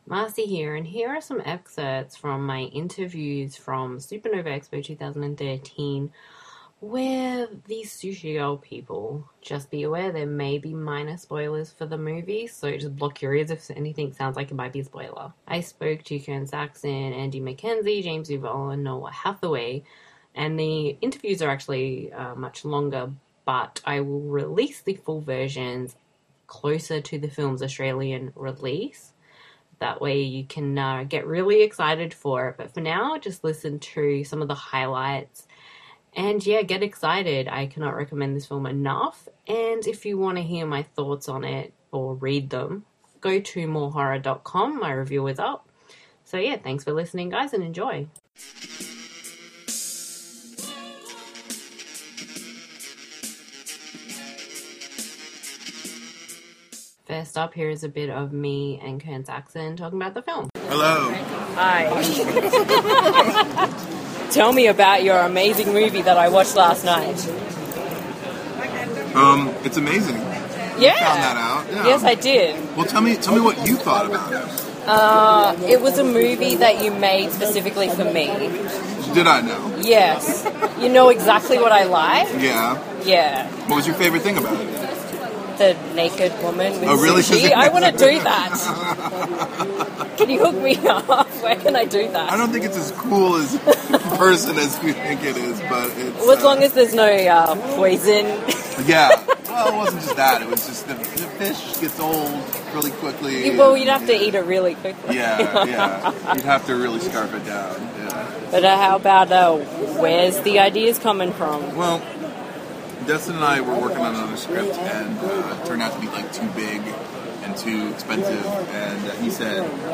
supanova-expo-2013-interviews_-sushi-girl.mp3